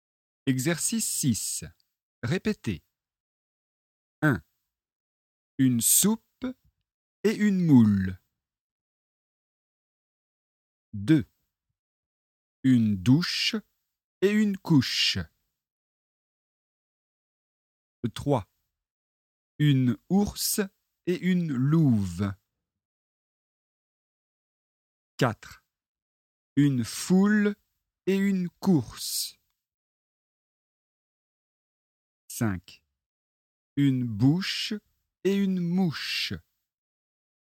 Leçon de phonétique, niveau débutant (A1).
Exercice 6 : répétez.